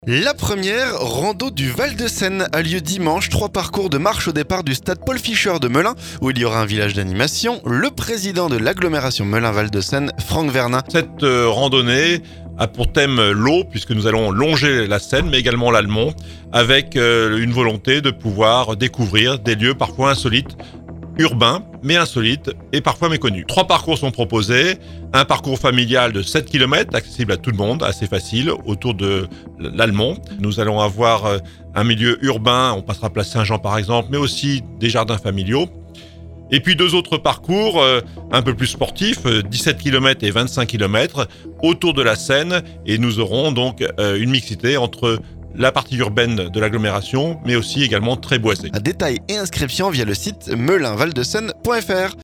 Trois parcours de marche au départ du stade Paul Fischer de Melun, où il y aura un village d'animations. Le président de l'agglomération Melun val de Seine, Franck Vernin.